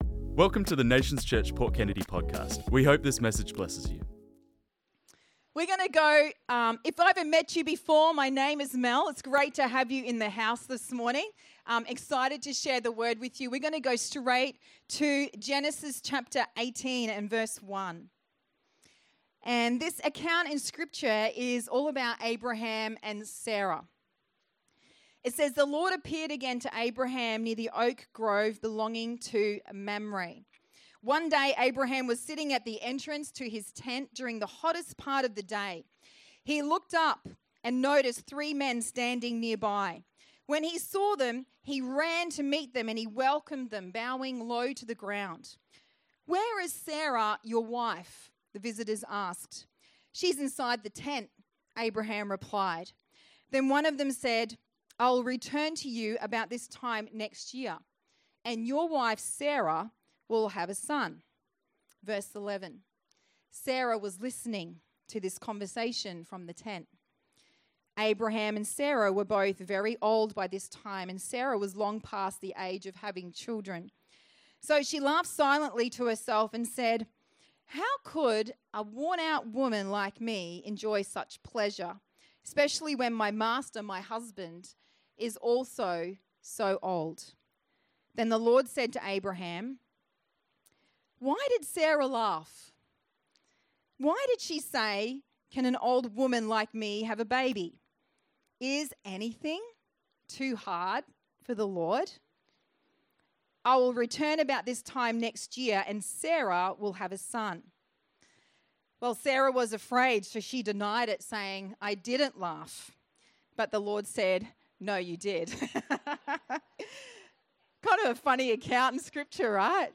This message was preached on Sunday the 14th September 2025